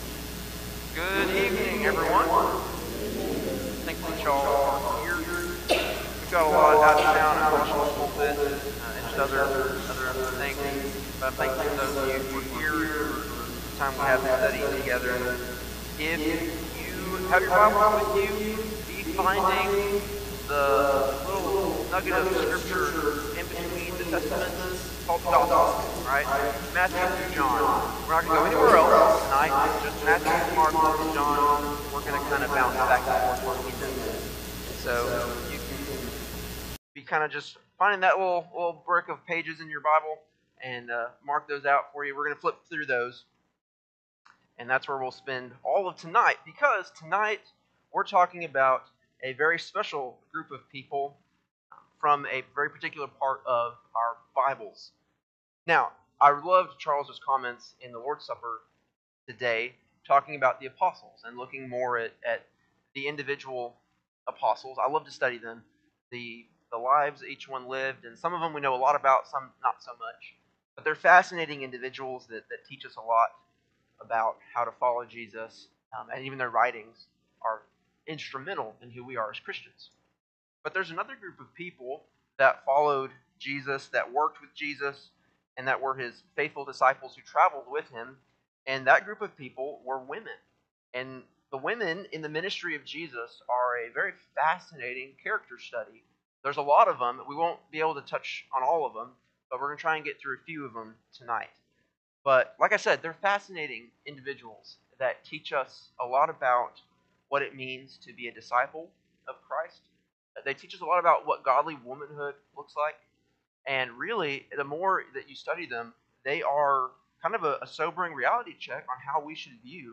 Sunday-PM-Sermon-8-17-25.mp3